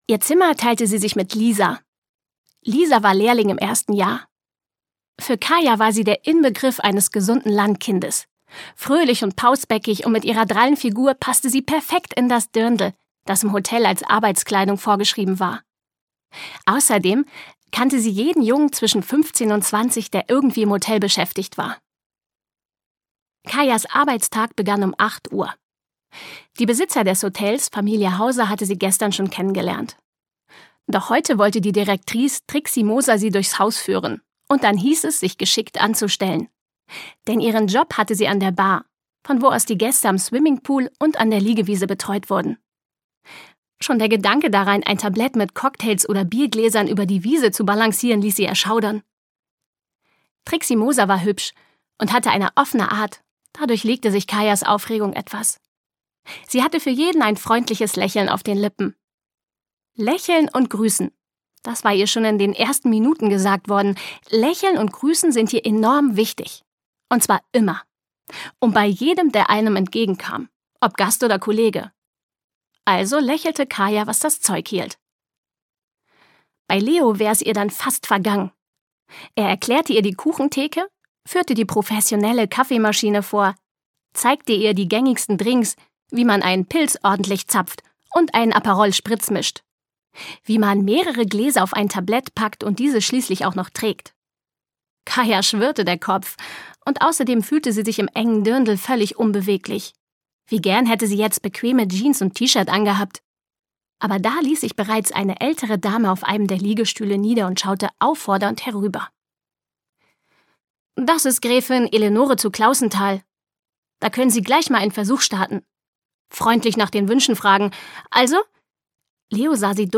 Hörbuch Frei wie der Wind - Kayas Pferdesommer, Gaby Hauptmann.